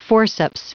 Prononciation du mot forceps en anglais (fichier audio)
Prononciation du mot : forceps